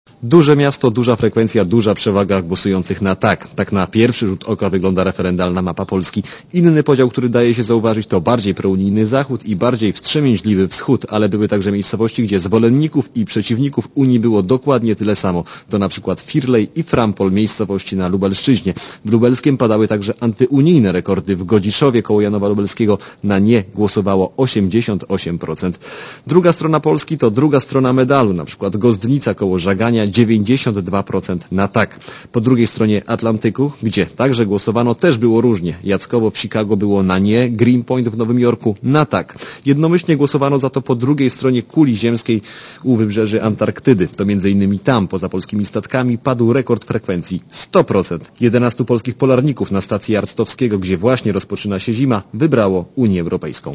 O tym, jak rozkładały sie głosy Polaków na referendalnej mapie Polski mówi reporter Radia Zet.